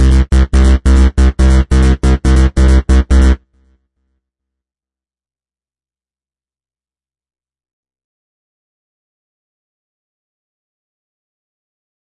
一个由我创造的贝斯。140 BPM